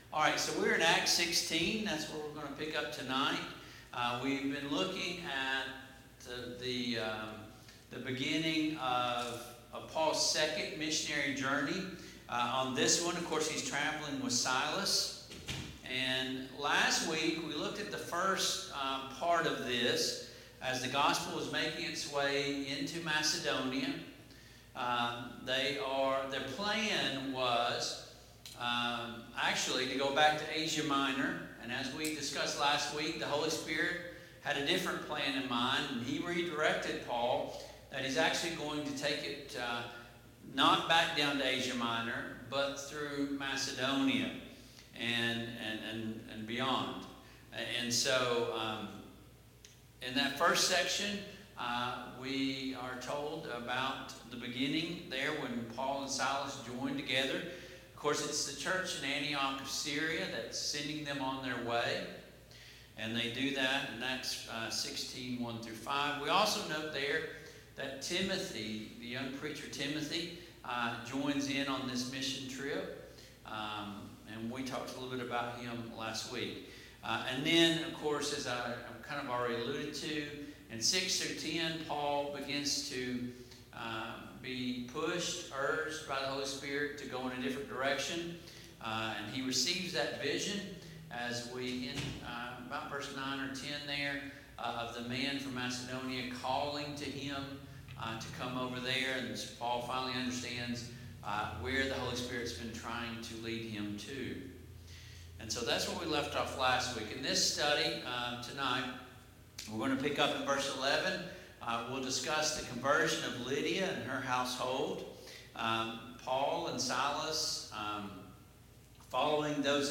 Passage: Acts 16:11-27 Service Type: Mid-Week Bible Study